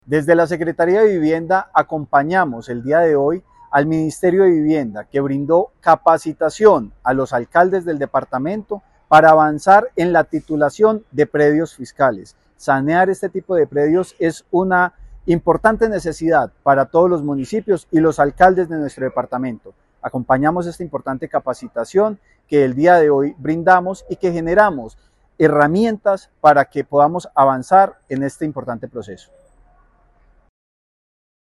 Francisco Javier Vélez Quiroga, secretario de Vivienda de Caldas.